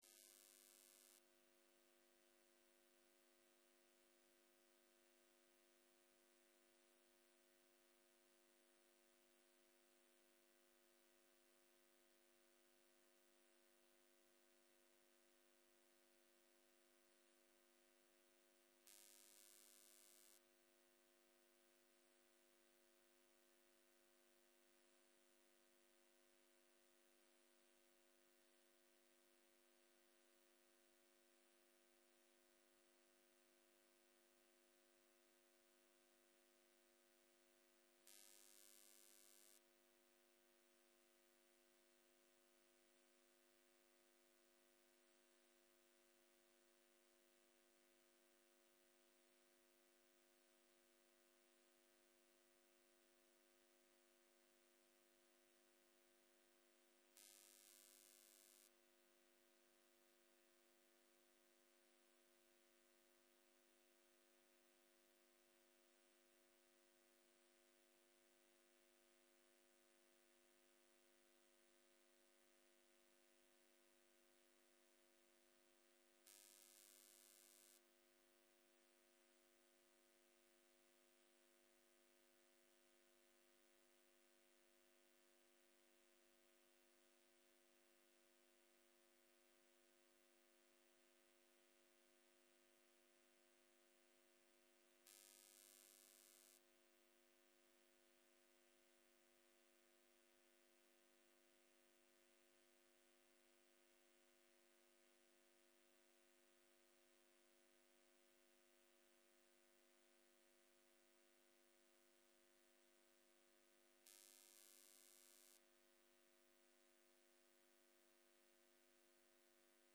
בוקר - הנחיות מדיטציה - עבודה עם מחשבות ורגשות
סוג ההקלטה: שיחת הנחיות למדיטציה שפת ההקלטה